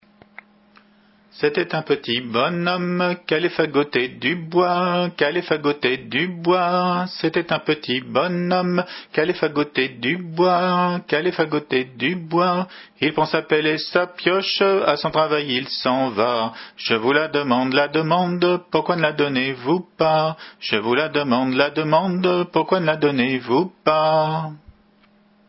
Hanter dro
Entendu au fest noz d'Allaire le 28 mai 92